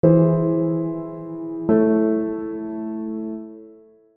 The following example demonstrates augmented chords resolving to chords containing a note a half step above the augmented fifth.
Eaug - A
Chords: V+ (Eaug) - I (A)